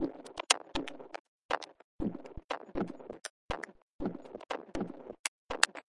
Drum Loops " 120 Metallic Beat 04
描述：4 bar Glitch / IDM鼓环。 120 BPM。
标签： 循环 120-BPM IDM 电子乐 毛刺 节拍
声道立体声